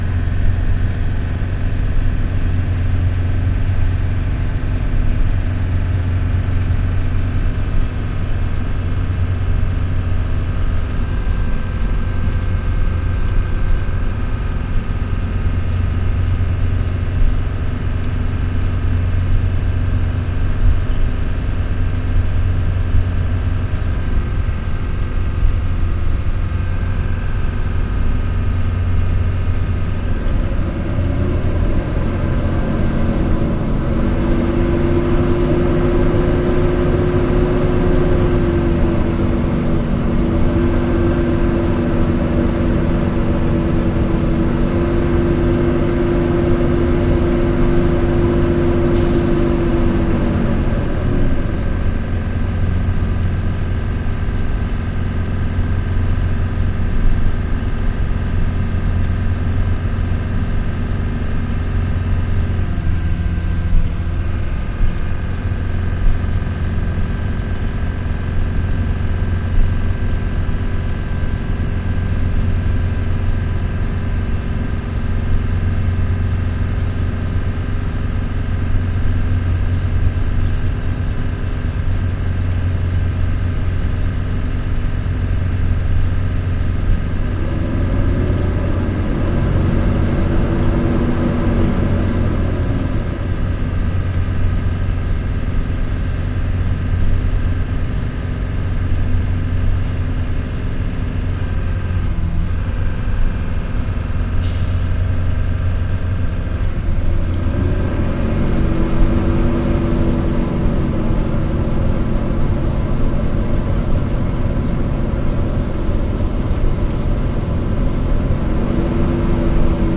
校车 "校车卡车 "快速行驶 高速公路前门区域1
描述：校车卡车int驾驶快速公路前门area1.flac
Tag: 高速公路 速度 卡车 速度快 公交车 INT 驾驶 学校